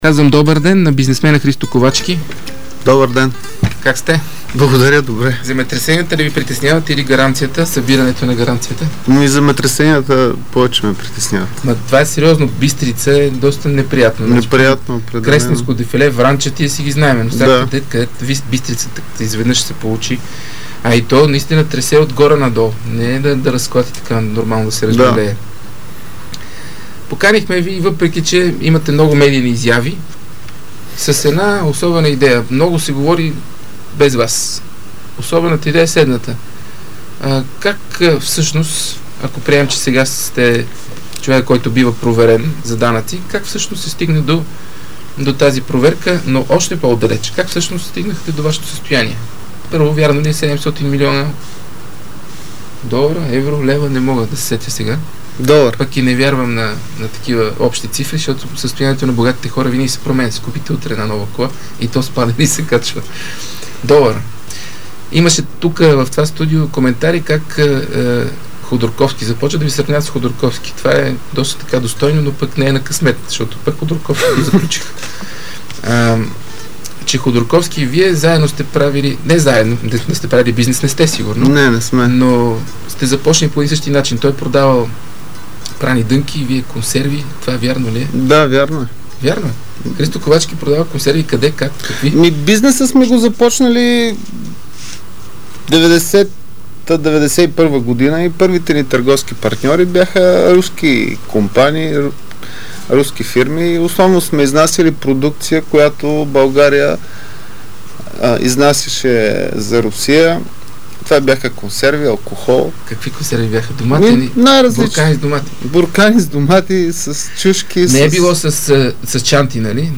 Интервю с бизнесмена Христо Ковачки в "Пропаганда" с Мартин Карбовски